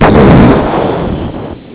bomb2.au